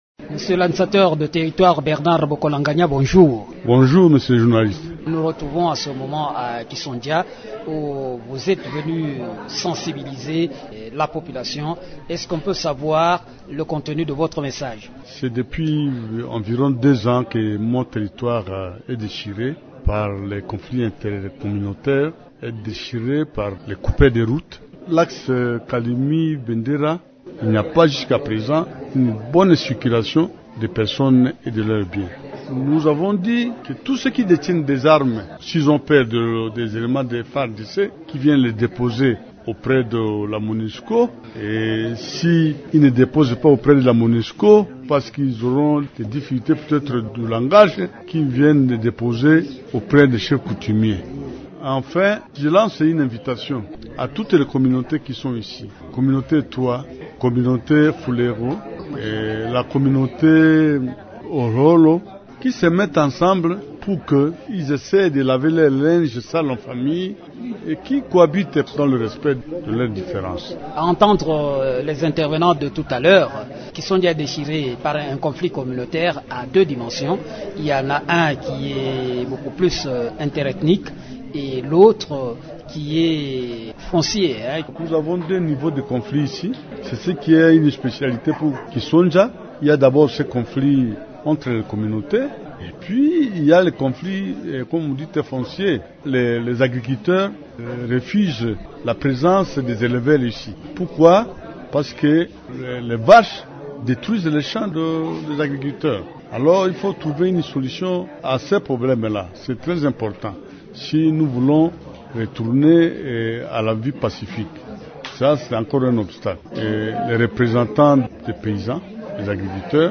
Bernard Bokolanganya est administrateur du territoire de Kalemie, dans la province du Tanganyika.